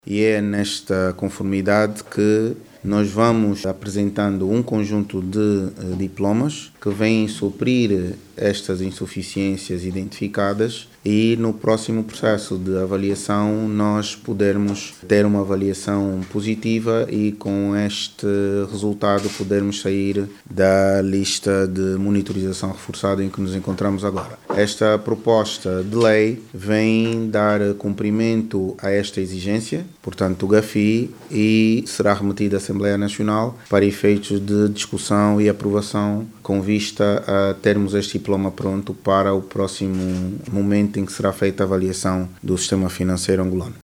Aprovada nesta quarta – feira a proposta de lei do regime jurídico do beneficiário efectivo tendo em vista o reforço da transparência e robustez do sector financeiro angolano com os olhos postos na próxima avaliação do Grupo de Acção Financeira Internacional, GAFI. O Ministro da Justiça e dos Direitos Humanos, Marcy Lopes, disse que o diploma que vai agora para a discussão e aprovação na Assembleia Nacional, vai suprir as inconformidades de natureza legal e operacional inseridas no conjunto das recomendações feitas pelo GAFI.